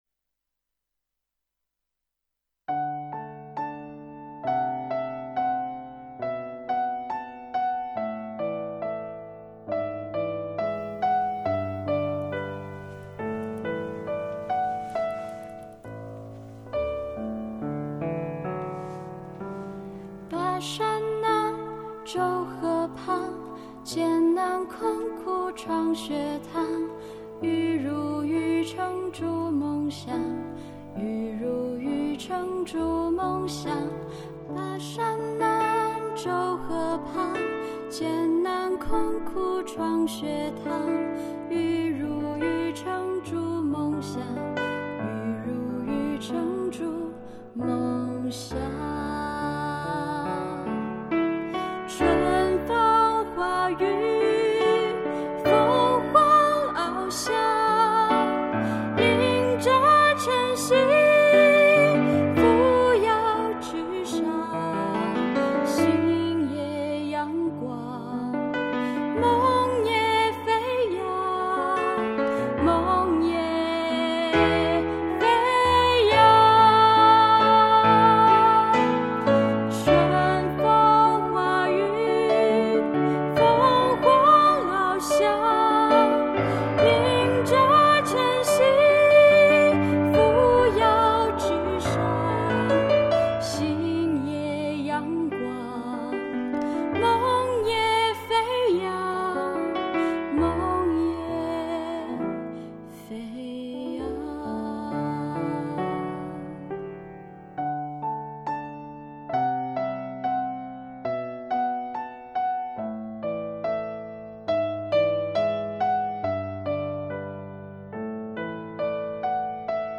《凤凰欲飞》词曲清新隽秀，旋律优美，充满青春气息，饱含学校历史文化和时代激情。
校歌女声D调.MP3